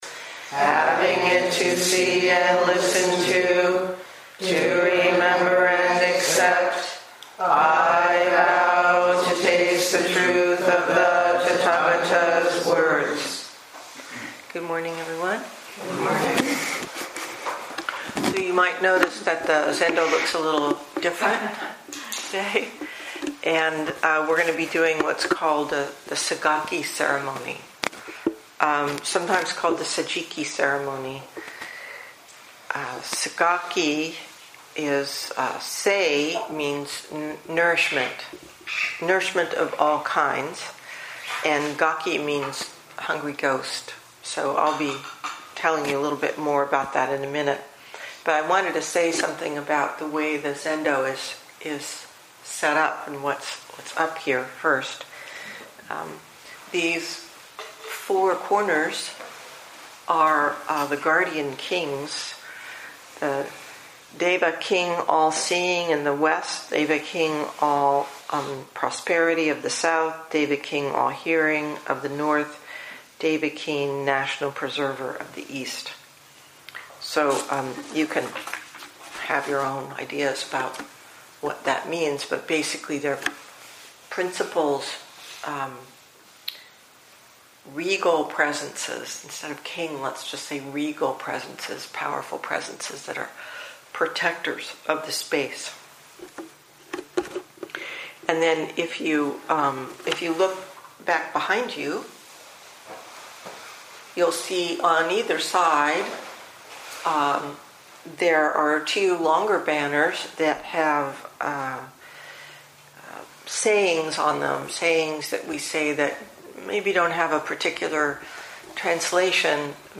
2018 in Dharma Talks